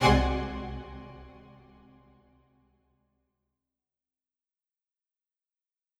Strings Hit 2 Spiccato
Bring new life to your videos with professional orchestral sounds.
A spiccato is one of the shortest and fastest sounds that a string instrument can make.  In this sample, you hear four sections of four different instruments from the orchestra which are violins, violas, violoncellos and double basses.
Strings-Hit-2-Spiccato.wav